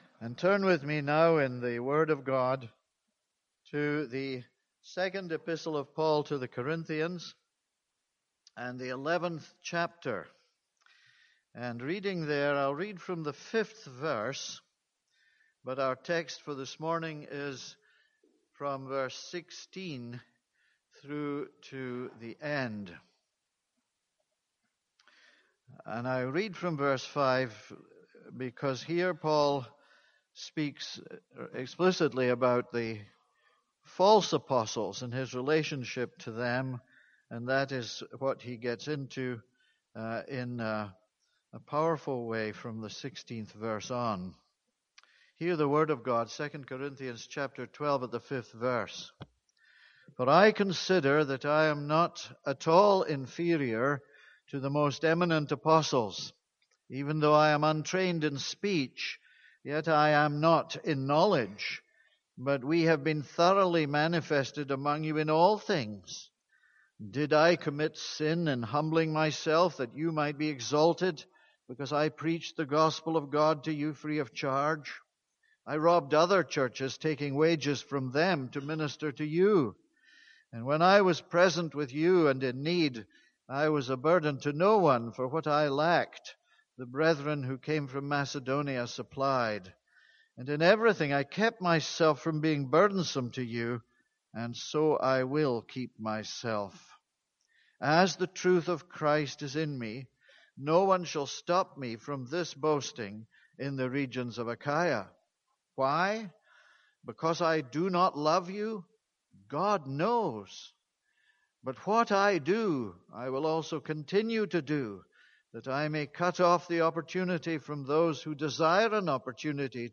This is a sermon on 2 Corinthians 11:16-33.